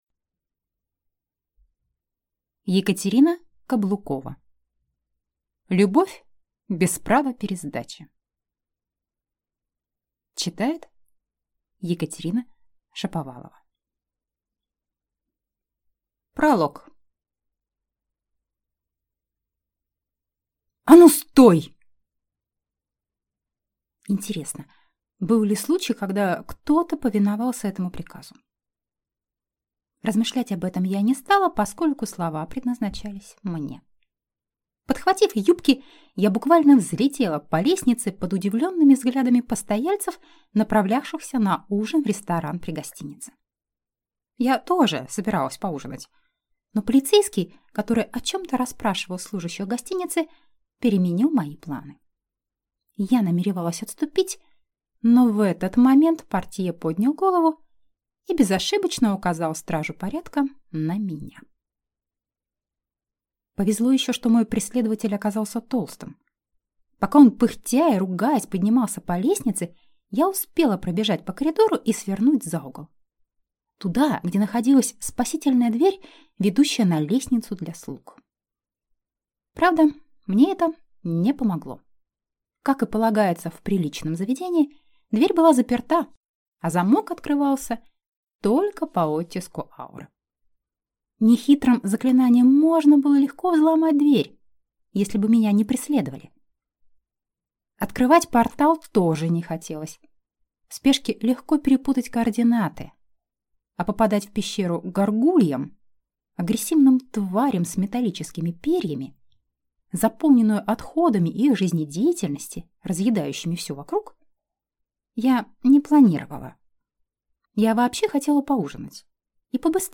Аудиокнига Любовь без права пересдачи | Библиотека аудиокниг